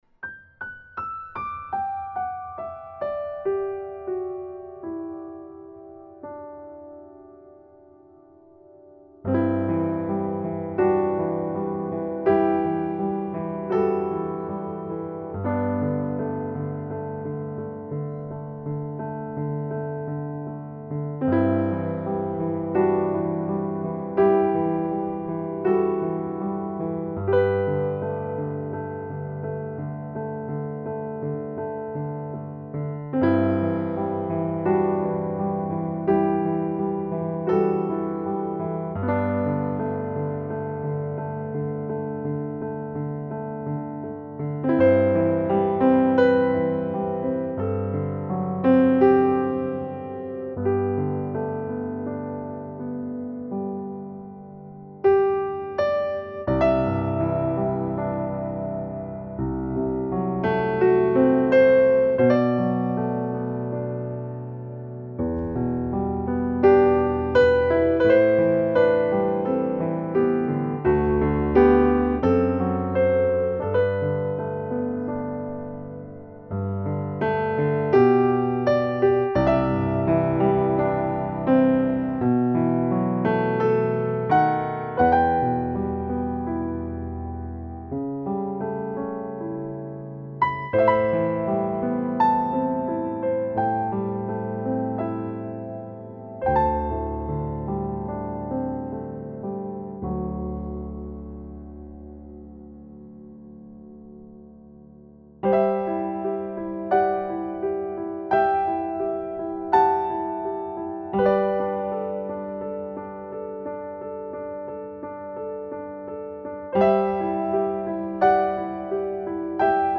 موسیقی بی کلام , آرامش بخش , پیانو , عصر جدید
موسیقی بی کلام آرامبخش موسیقی بی کلام پیانو